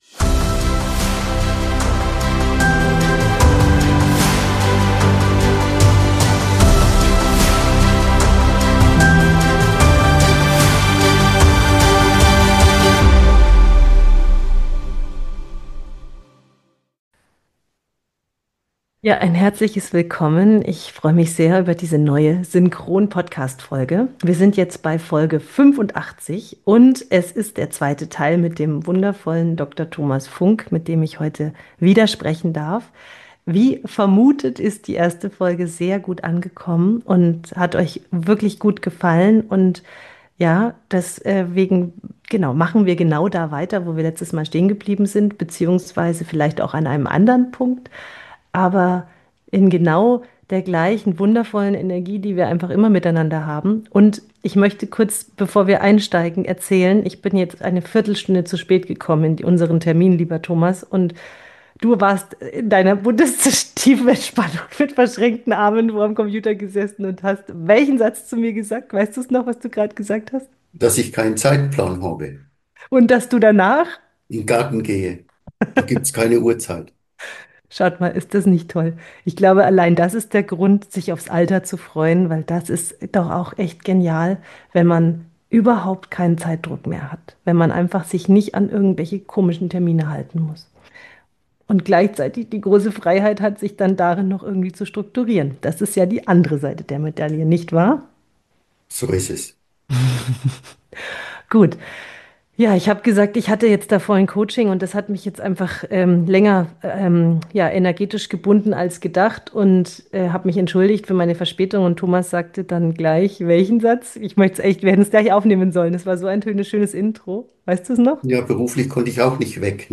Ein ehemaliger Chirurg, der offen über Burnout, Lebensentscheidungen und den Mut zum Neuanfang spricht.
Ein tiefes, ehrliches Gespräch über Schmerz, Sinn und die Kunst, das Leben immer wieder neu zu gestalten.